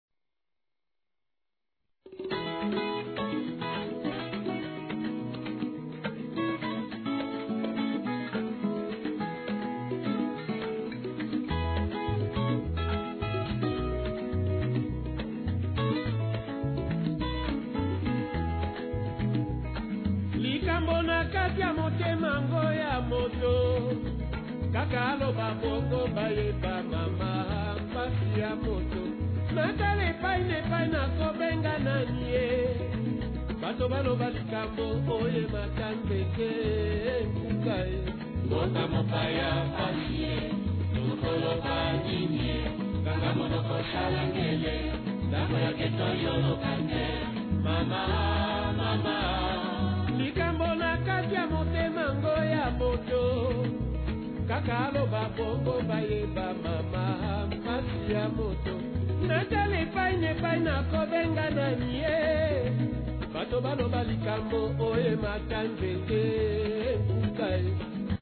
music is gentle, yet filled with passion.